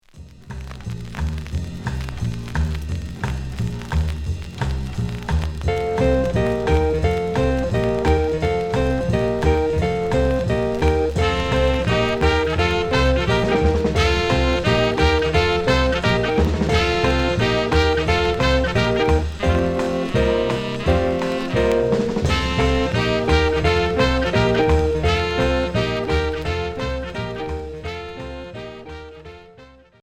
Hully gully